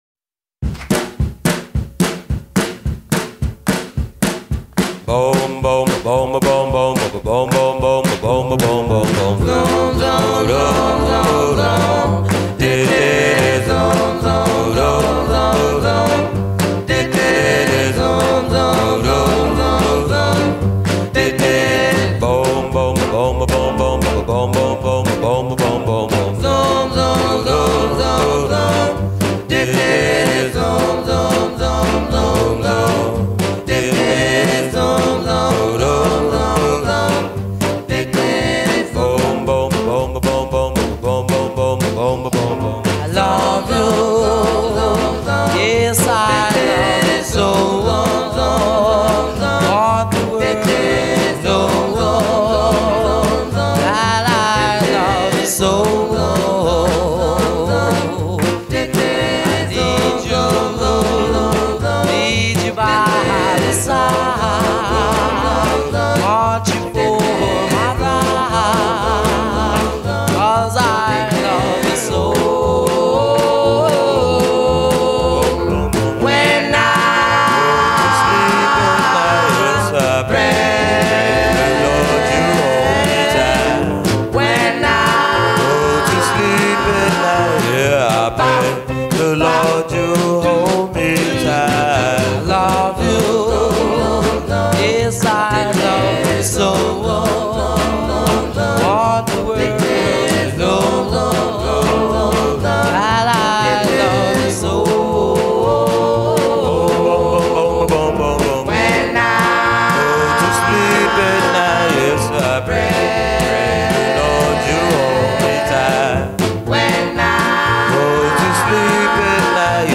Tag: Doo Wop